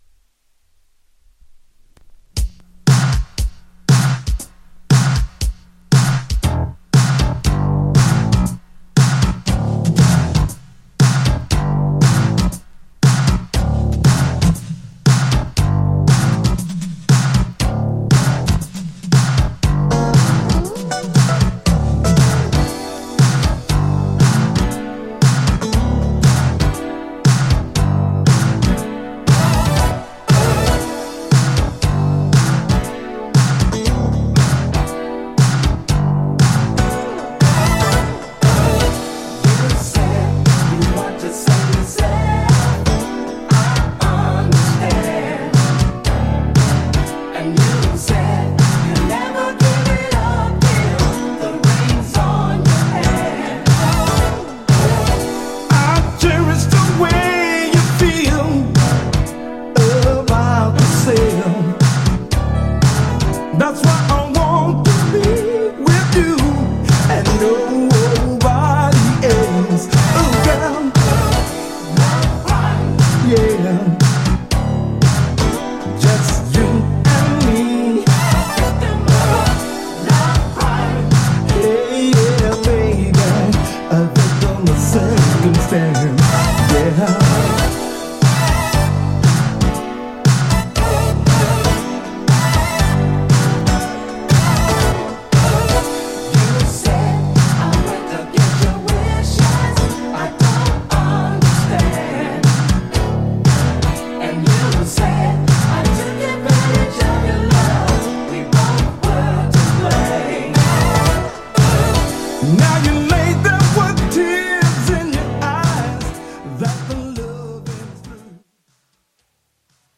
(DANCE SIDE)
ジャンル(スタイル) DISCO / FUNK / SOUL